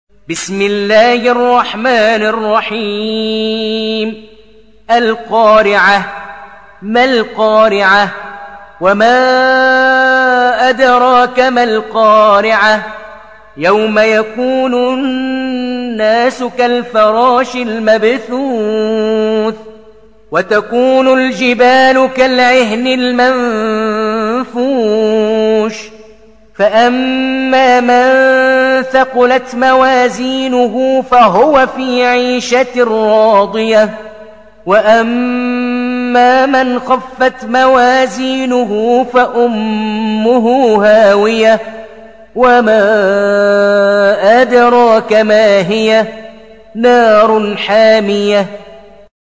(روایت حفص)